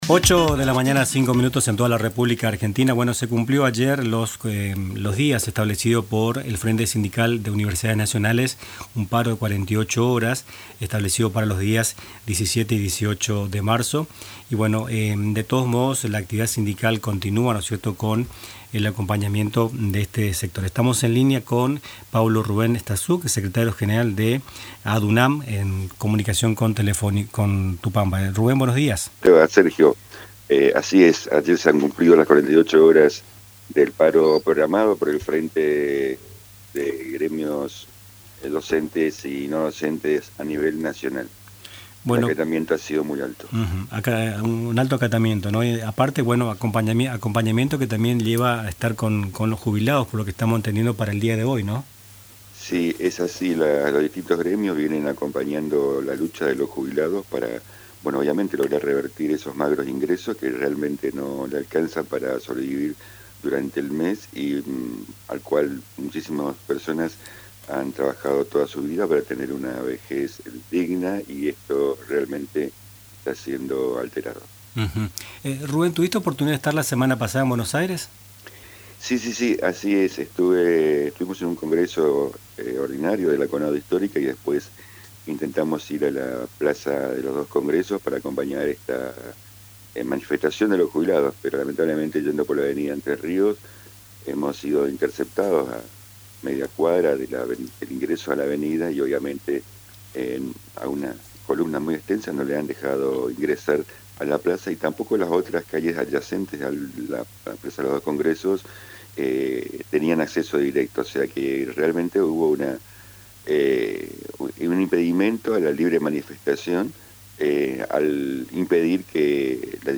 En “Nuestras Mañanas”, entrevistamos